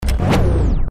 Home gmod sound vehicles enzo
shutdown.mp3